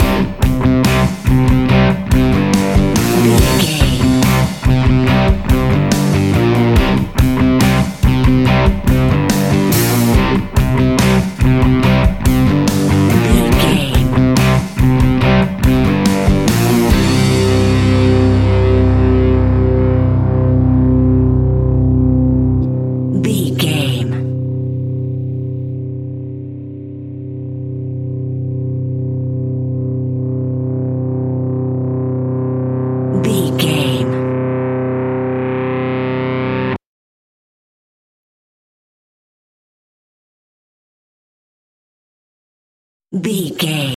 Aeolian/Minor
energetic
driving
aggressive
electric guitar
bass guitar
drums
hard rock
distortion
distorted guitars
hammond organ